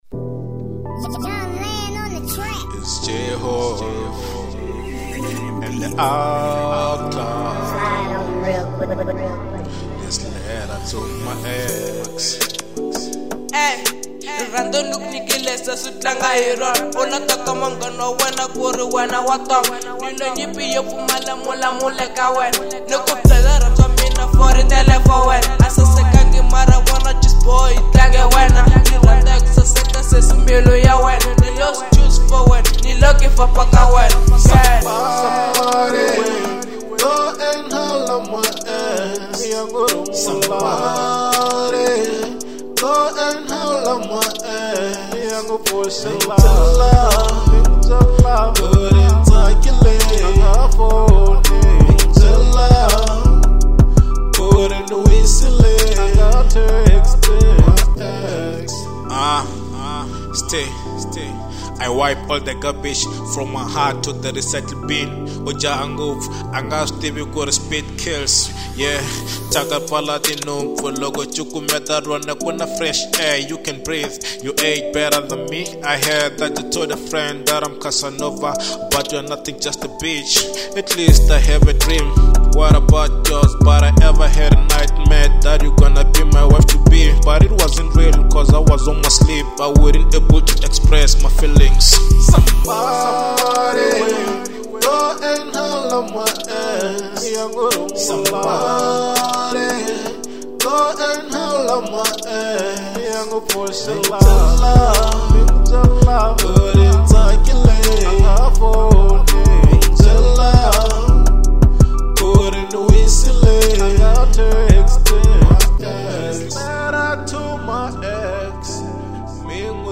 02:46 Genre : Hip Hop Size